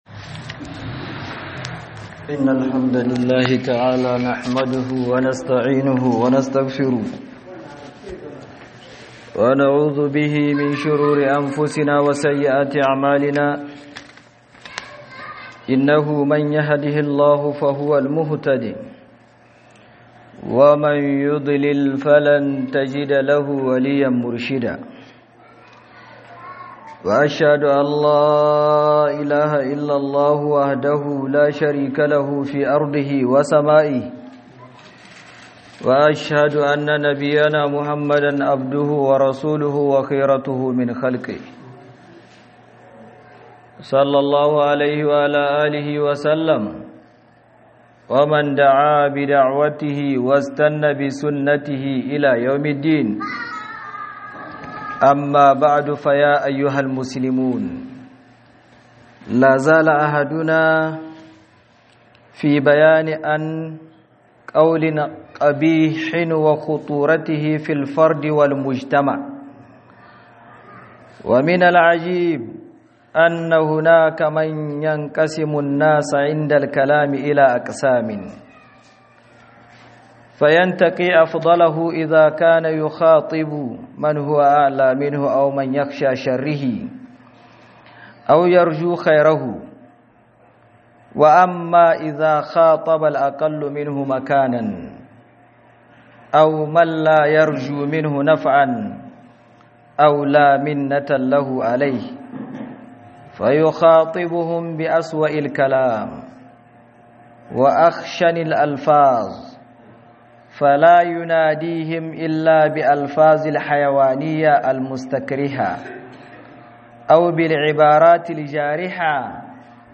2026-04-10KA GAYI MAGANA ME KYEW - HUDUBA